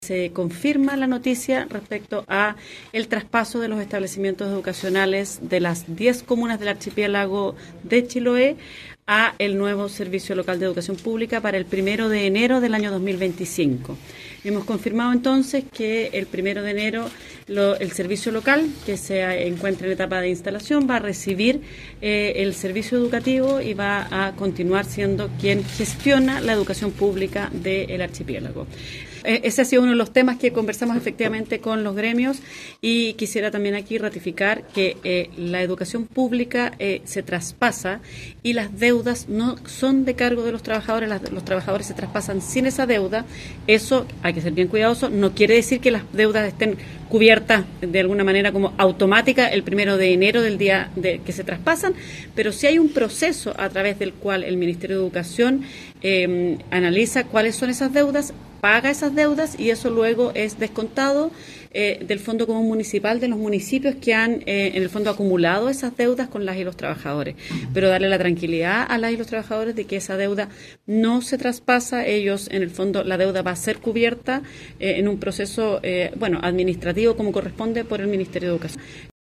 Alejandra Arratia sostuvo que los trabajadores se traspasan sin esa deuda y detalló que habrá un proceso en el tiempo donde será cubierta bajo un procedimiento que está normado en la nueva estructura.